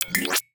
UIClick_Equip Power Up Exit 01.wav